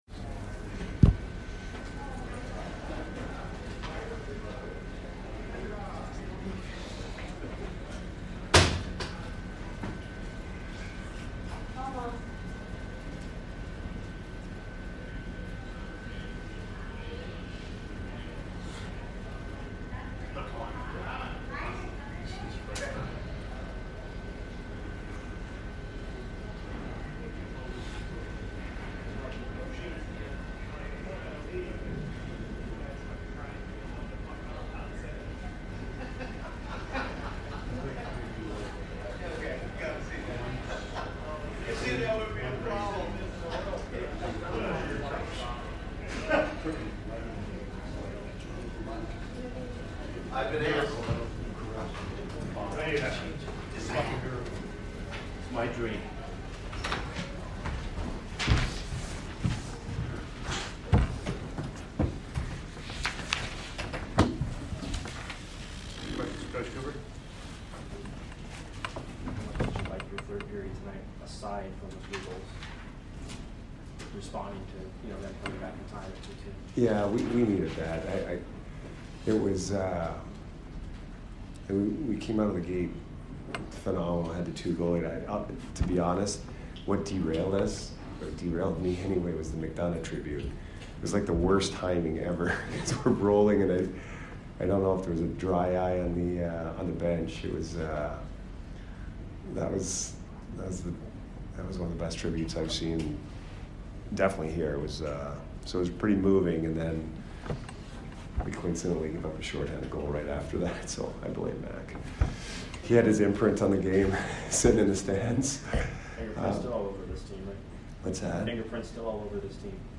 Head Coach Jon Cooper Post Game 12/8/22 vs NSH